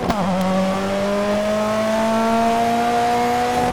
Index of /server/sound/vehicles/lwcars/delta